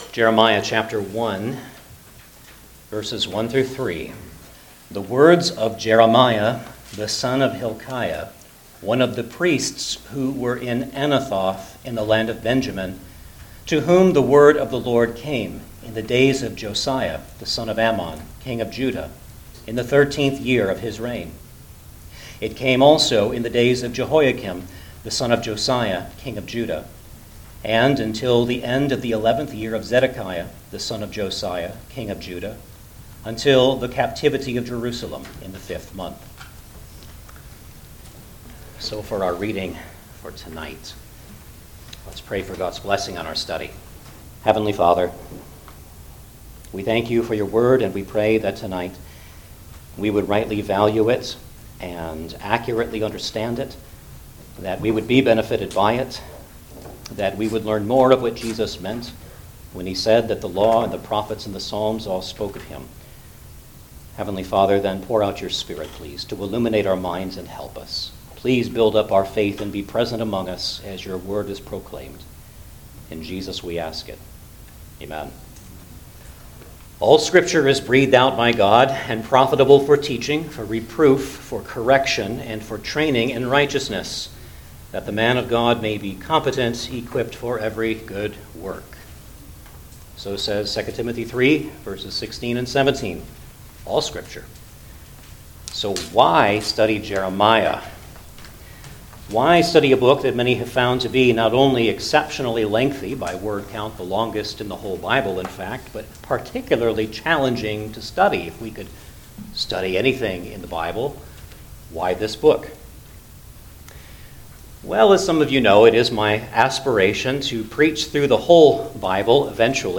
Jeremiah Passage: Jeremiah 1:1-3 Service Type: Sunday Evening Service Download the order of worship here .